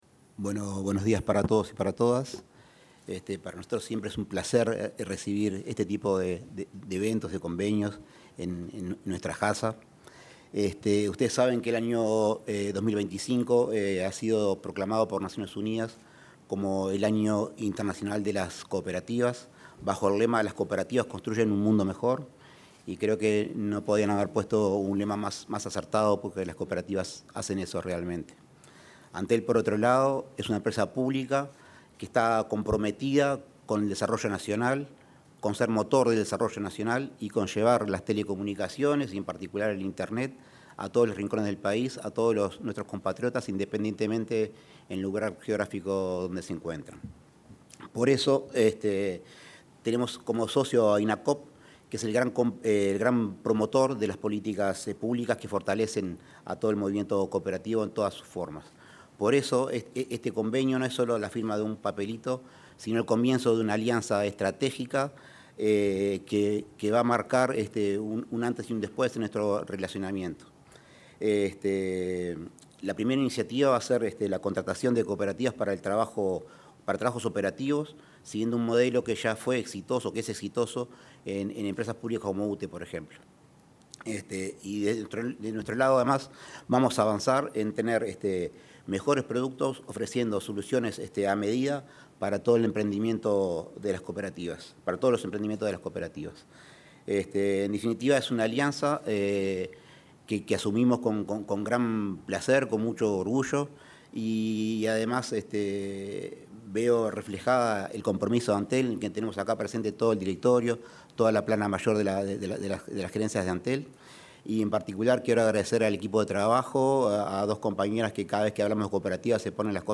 Palabras de los presidentes de Antel, Alejandro Paz, y el Inacoop, Graciela Fernández
Palabras de los presidentes de Antel, Alejandro Paz, y el Inacoop, Graciela Fernández 03/09/2025 Compartir Facebook X Copiar enlace WhatsApp LinkedIn Los presidentes de la Administración Nacional de Telecomunicaciones (Antel), Alejandro Paz, y el Instituto Nacional del Cooperativismo (Inacoop), Graciela Fernández, se expresaron durante la firma de un convenio de colaboración interinstitucional.